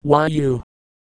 Worms speechbanks
illgetyou.wav